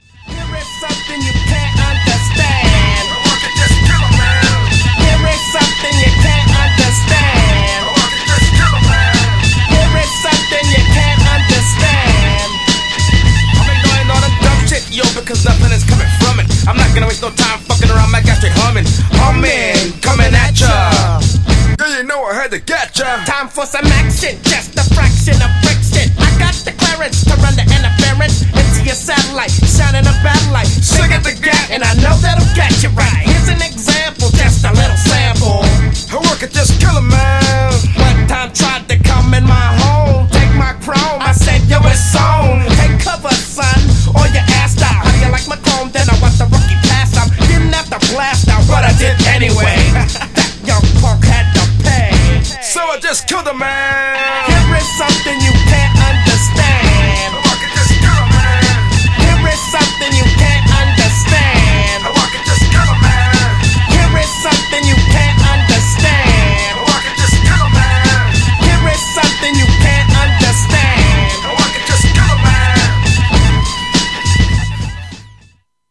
GENRE Hip Hop
BPM 101〜105BPM
アツイフロウ